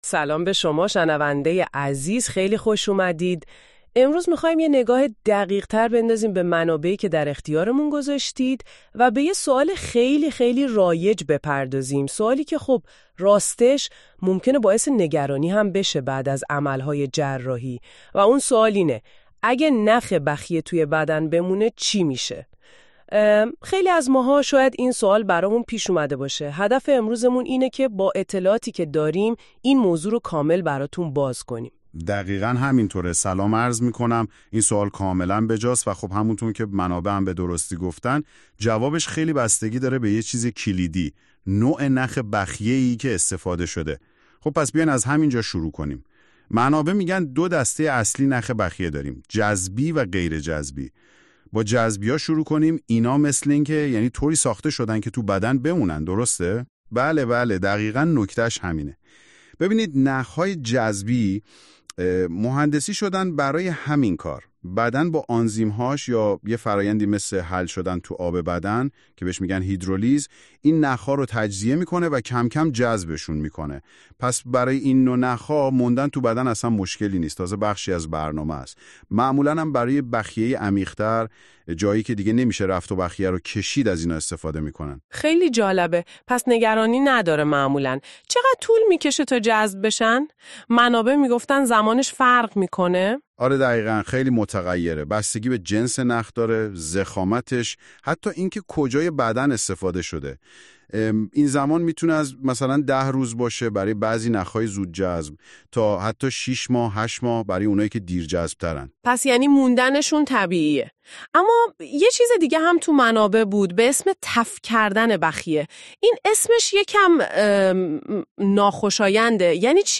همچنین شما می توانید در صورت تمایل پادکست زیر را که به طور کامل در این مورد با کارشناسان مورد بحث و بررسی قرار گرفته است را گوش کنید.